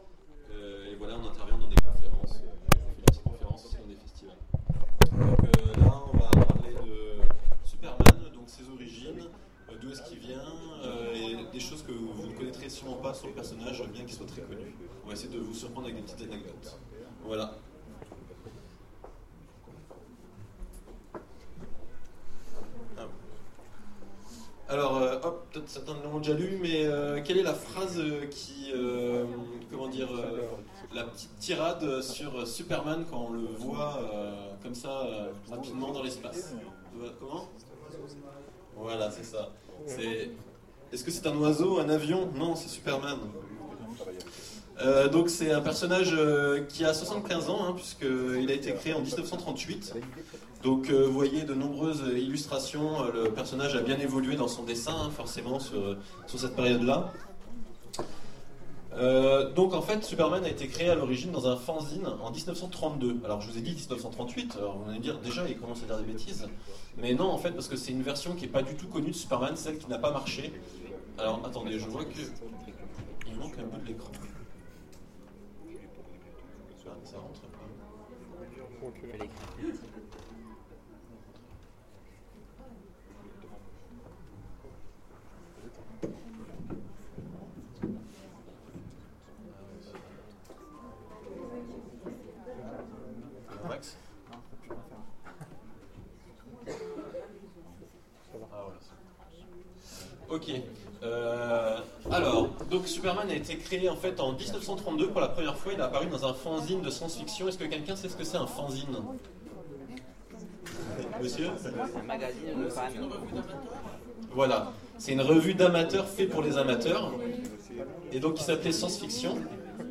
Autres mondes 2013 : Conférence Les DC's Comics